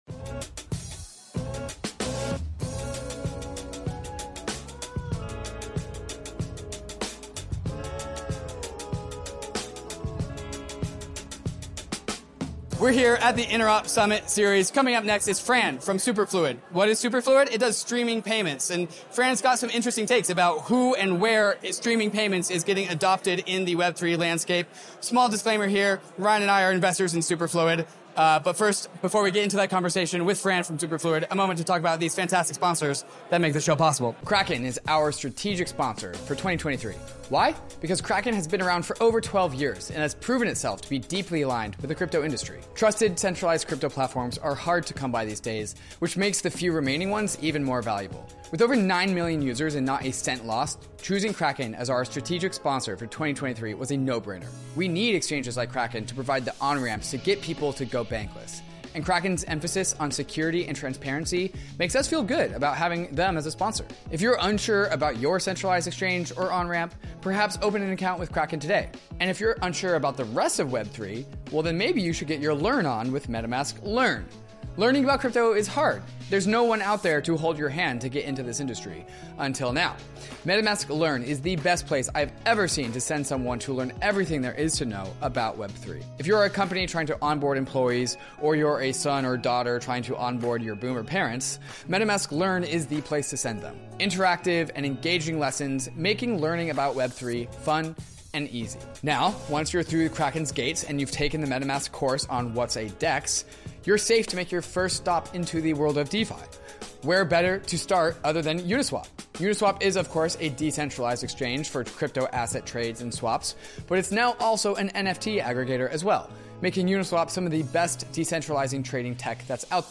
ETHDenver 2023 Interview #2
Sometimes, the frontier is at a crypto conference.